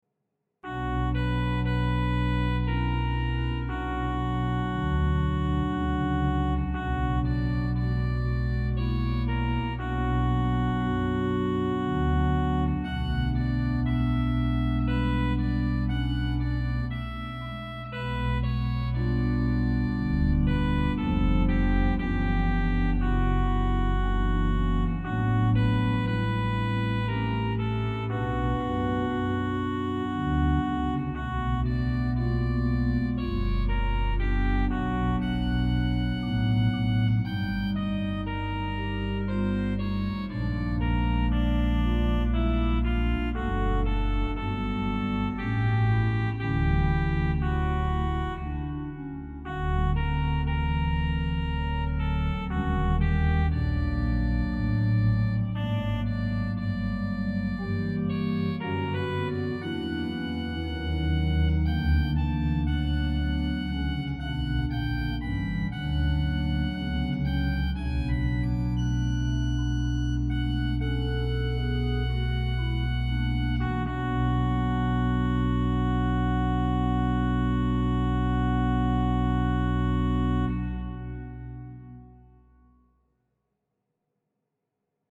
No additional effect processing have been added to the recording. The tail of release is the original of the samples.
E.Mulet - Chant funebre Pedal: Subbasso 16  + Tappato 8 + Flauto 4
Great: Flauto a Camino 8
Swell: Principale 4 + Tromba Piccola 8
Unions: Manual 1 to Pedal